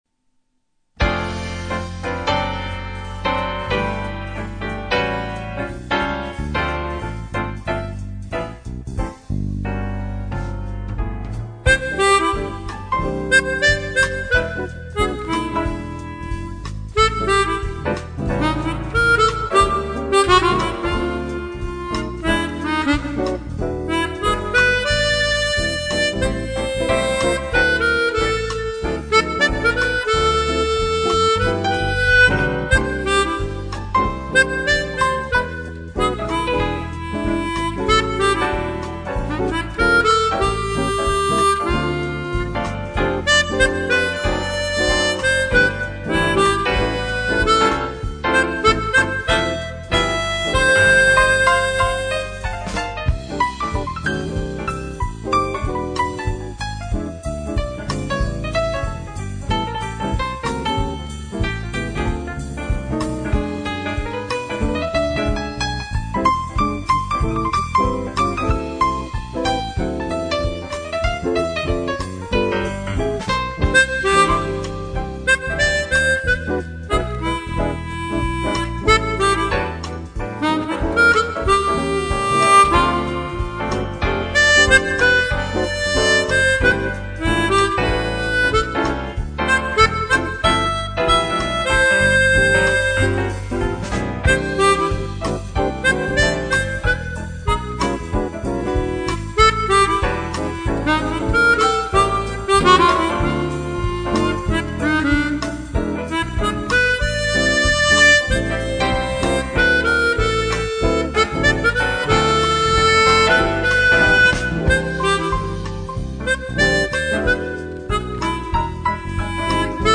Ca swing!